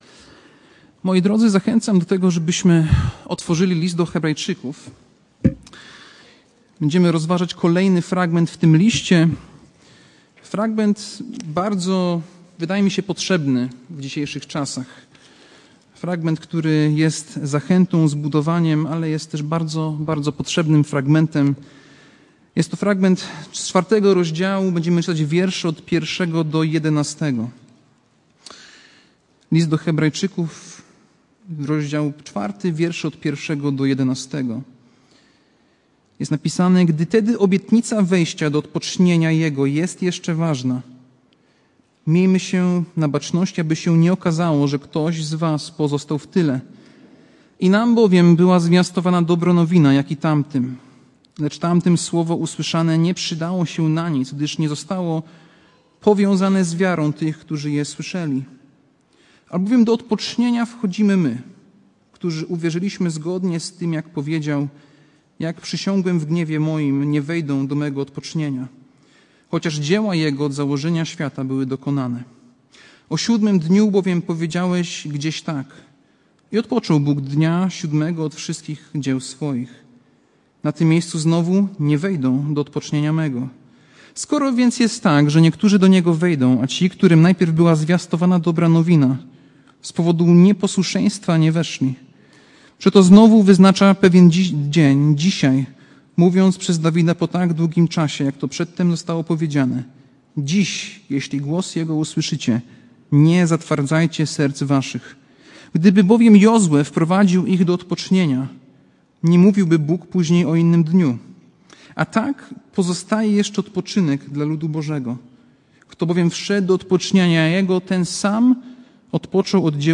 Passage: List do Hebrajczyków 4, 1-13 Kazanie